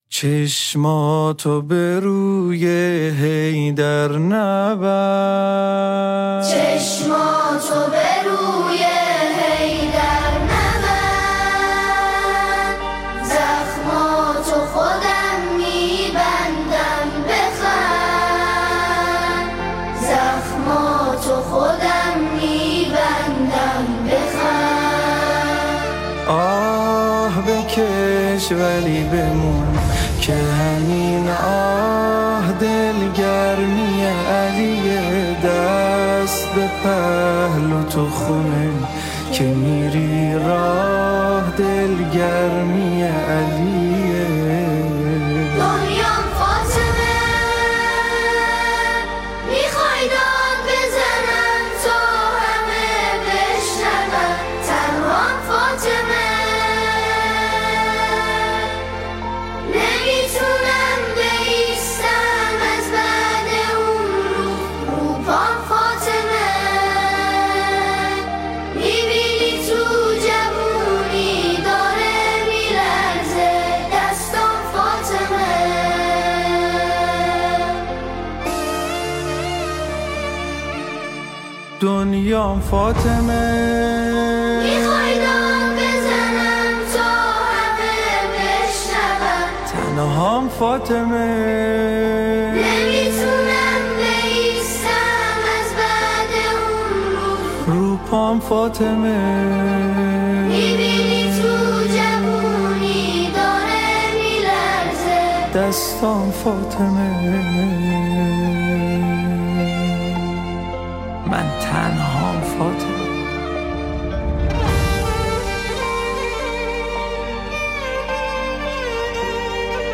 با نوای دلنشین و زیبای
ویژه ی ایام فاطمیه